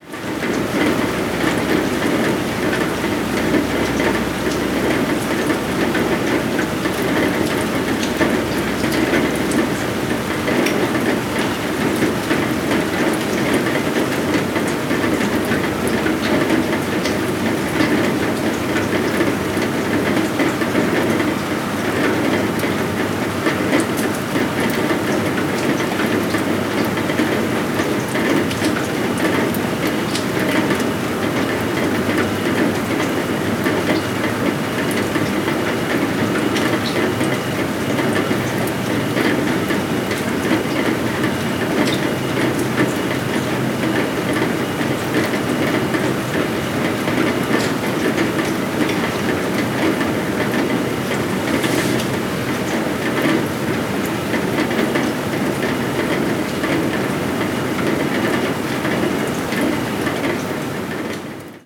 tarde-de-lluvia.wav